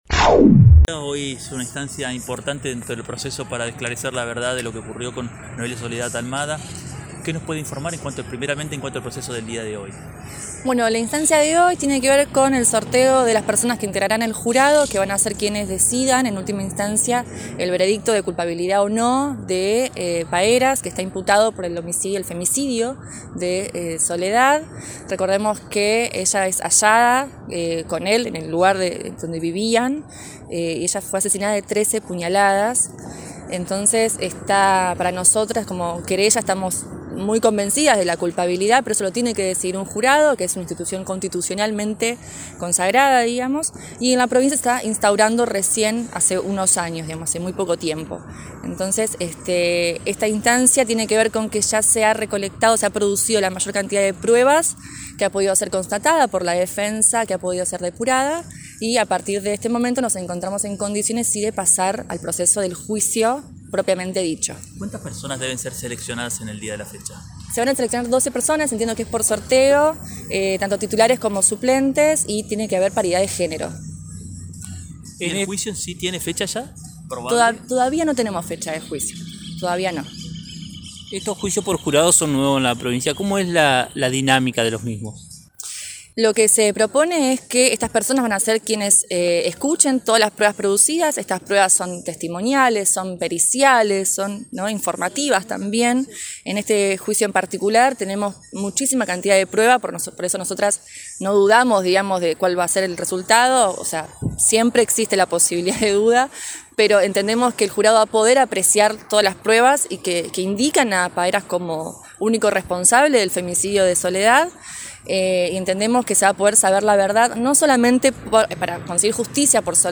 En diálogo con este medio